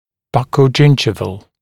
[ˌbʌkəu’ʤɪnʤɪvəl][ˌбакоу’джиндживэл]щечно-десневой